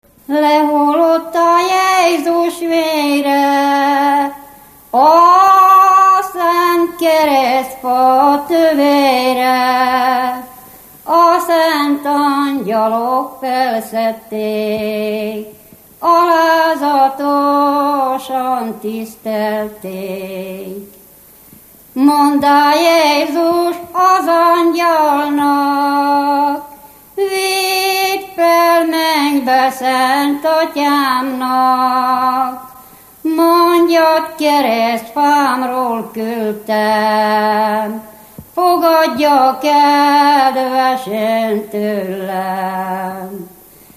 Erdély - Szilágy vm. - Kárásztelek
Műfaj: Húsvéti ének
Stílus: 4. Sirató stílusú dallamok